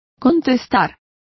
Complete with pronunciation of the translation of answering.